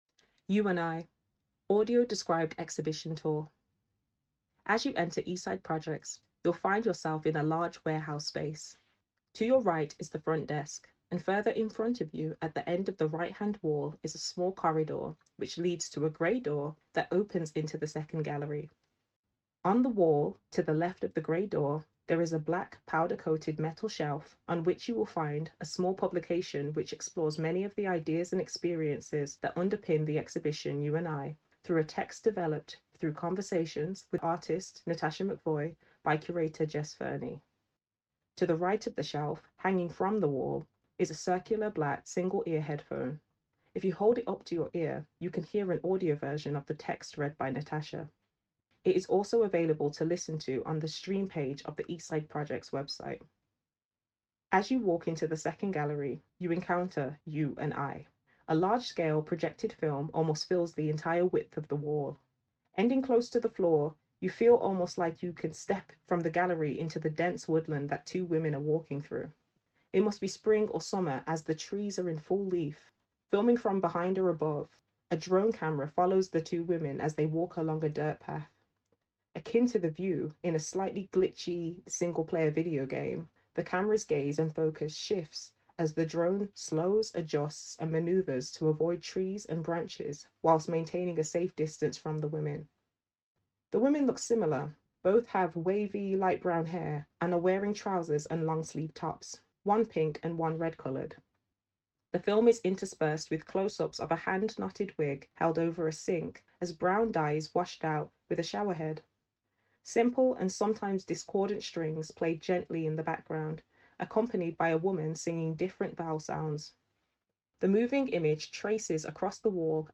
Audio Described Tour – U & I